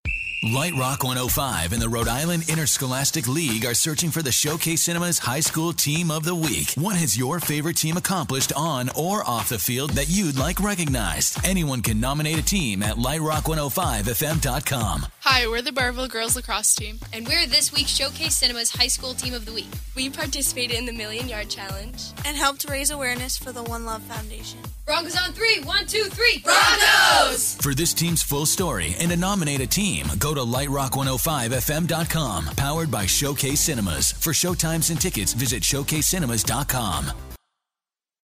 Lite Rock 105 On-Air Spot